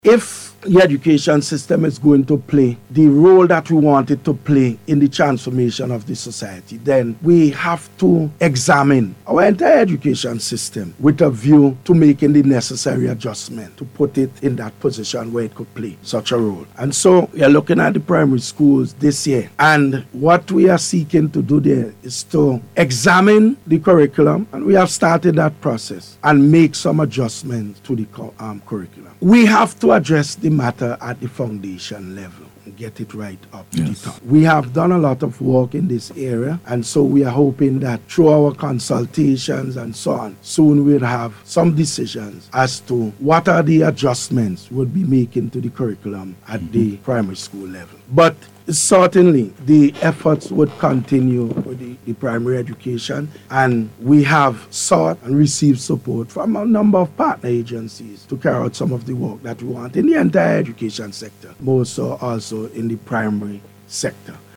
He was speaking on matters relating to education on NBC ‘s Face to Face Programme on Wednesday.